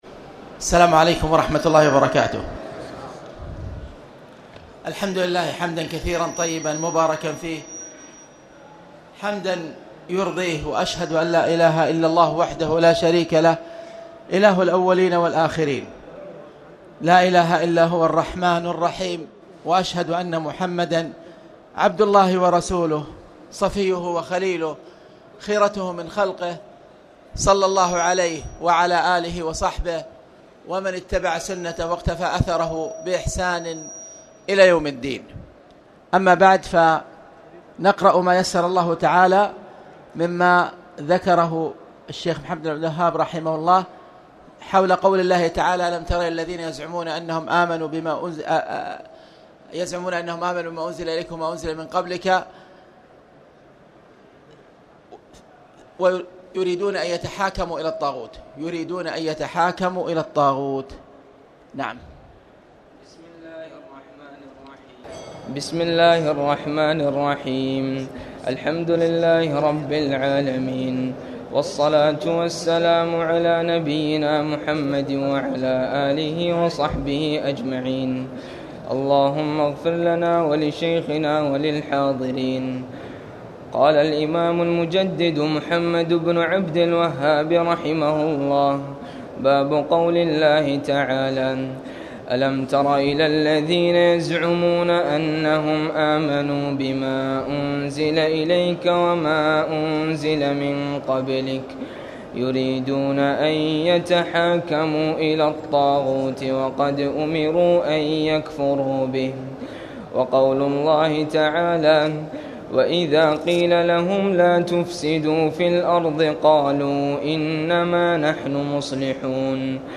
تاريخ النشر ١١ رمضان ١٤٣٨ هـ المكان: المسجد الحرام الشيخ